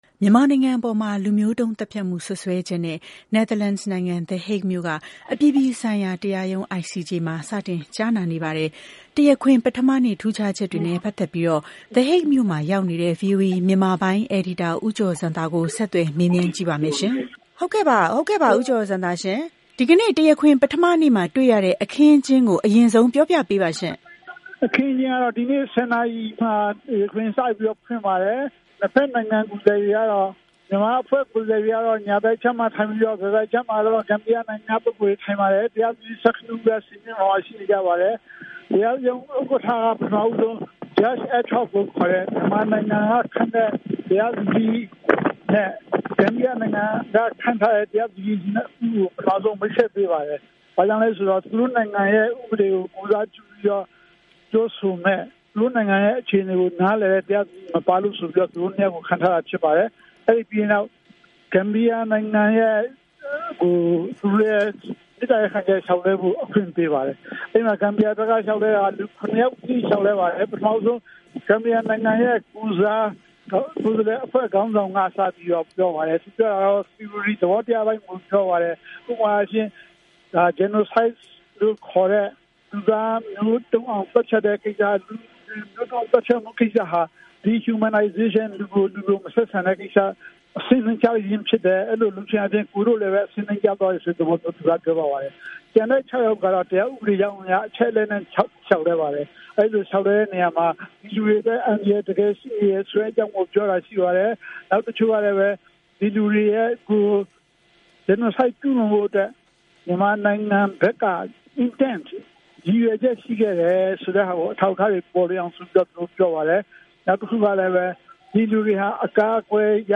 ICJ တရားရုံး ကြားနာပွဲ Skype က ဆက်သွယ်မေးမြန်းချက်